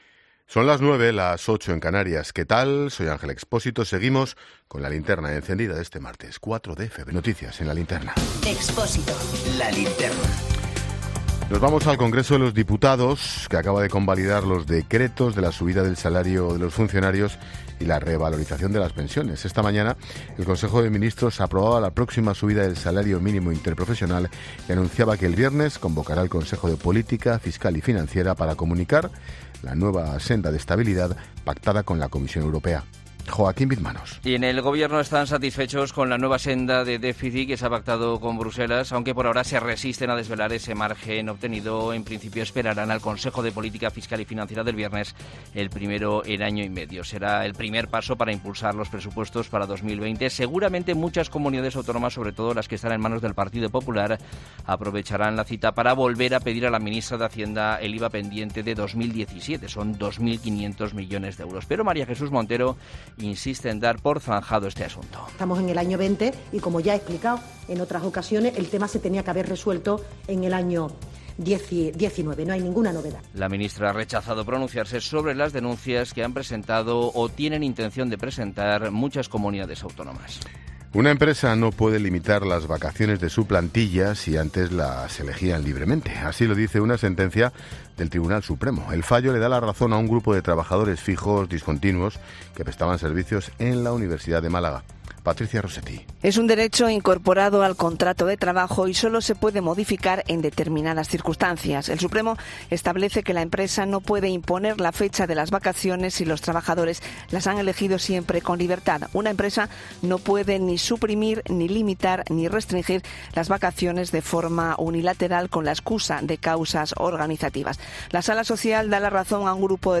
Boletín informativo de COPE del 4 de febrero de 2020 a las 21 horas